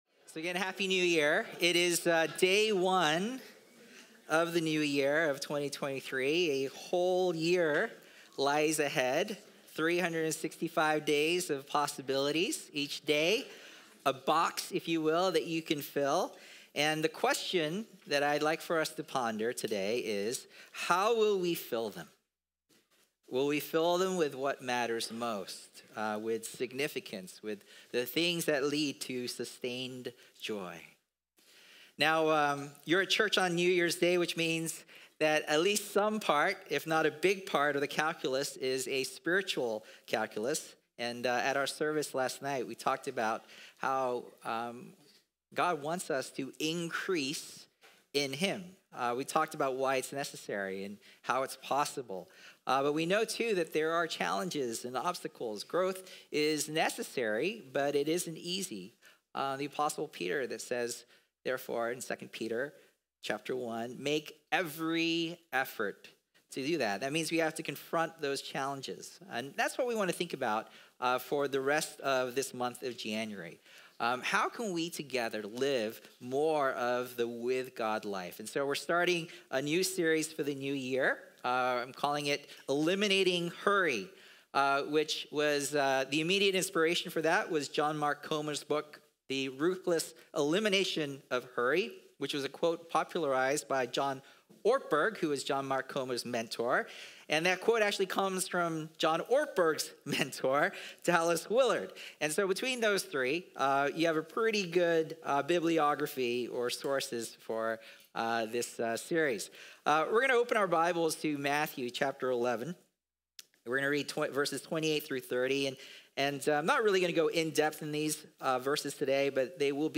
Sermons | Symphony Church